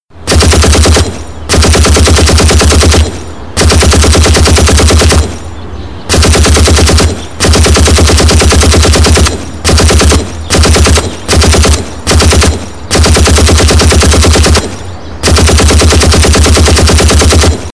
Рингтон Выстрелы из пулемета
Звуки на звонок